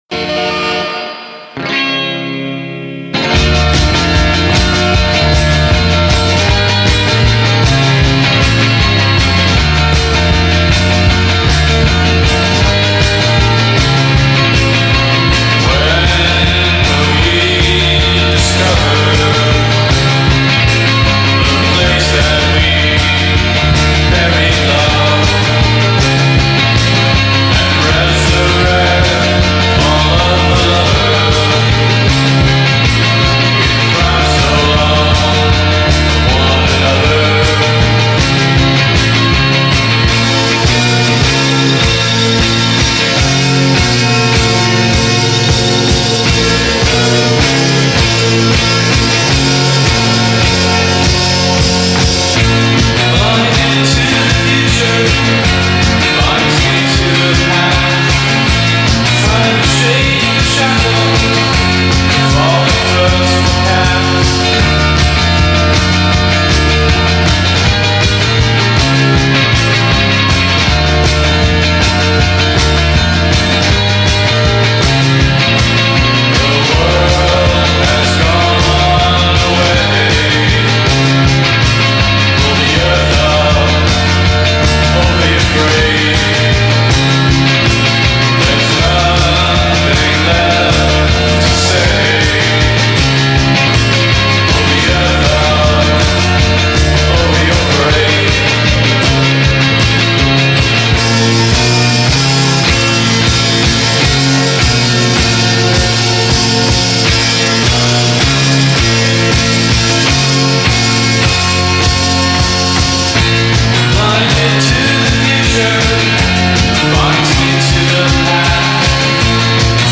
reverb, broody guitar